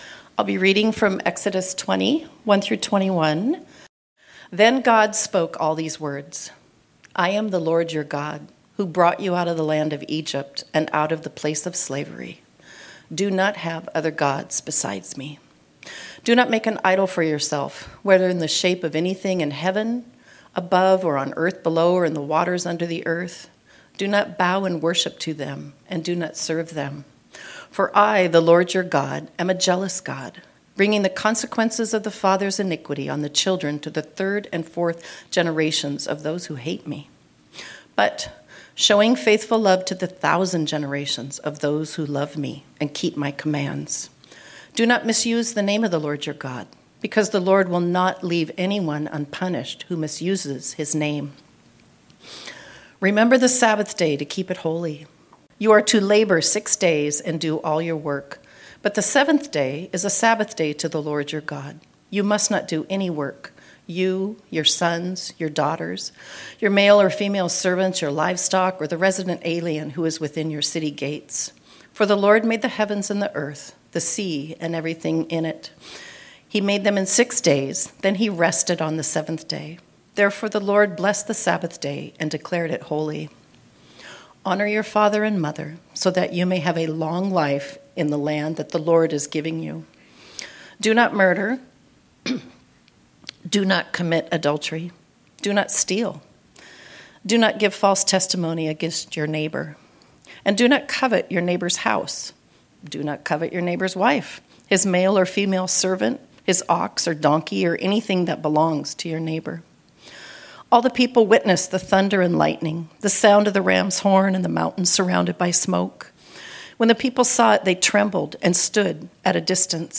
This sermon was originally preached on Sunday, March 23, 2025.